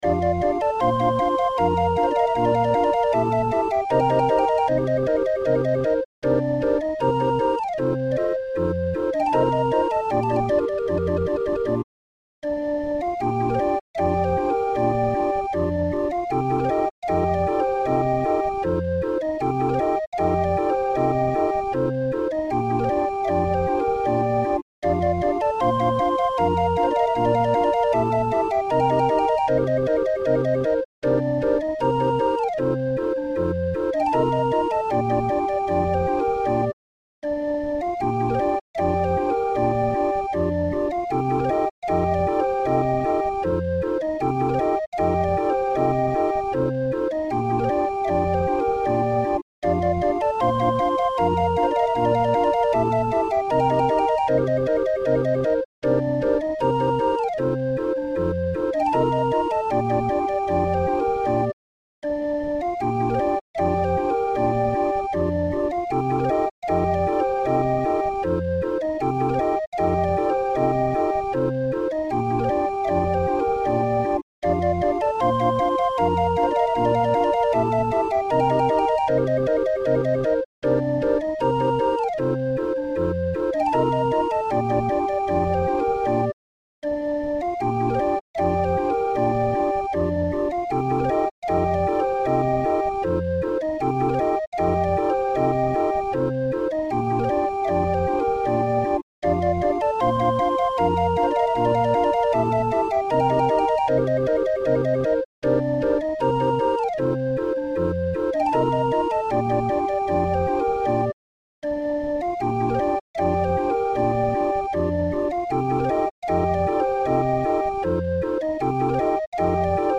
Musikrolle 20-er Raffin